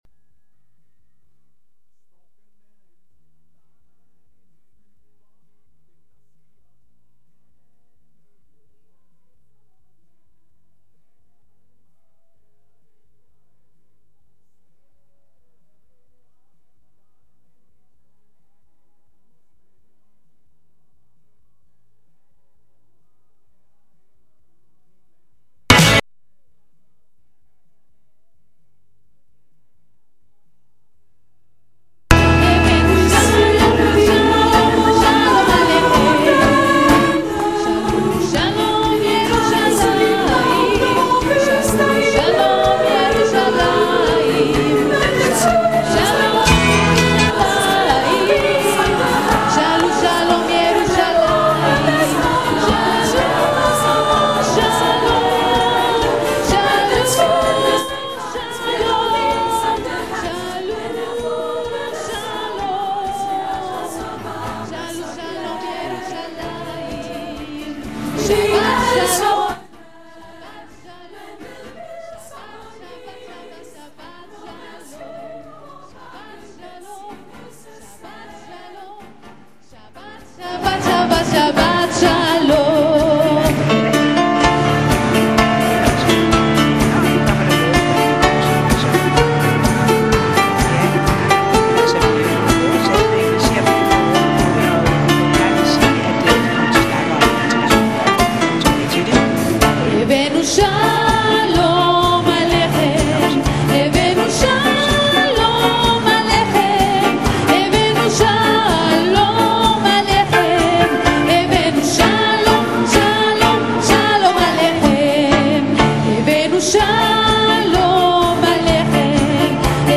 Diskussion om Integration fra faglig synspunkt